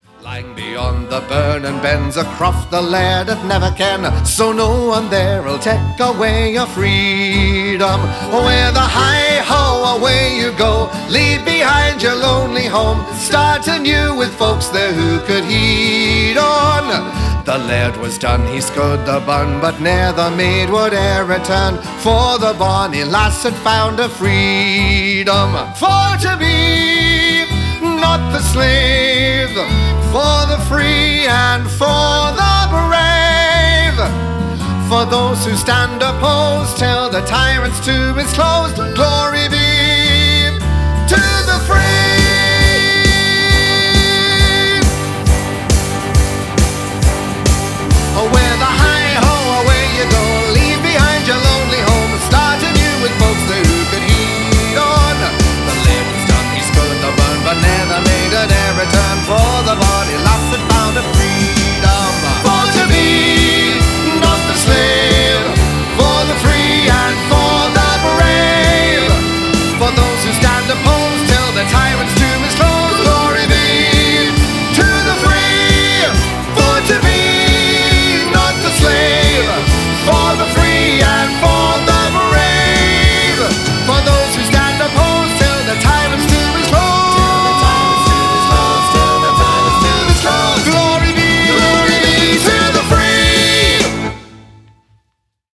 These are performed in similar styles to the finished versions but have different orchestrations and keys.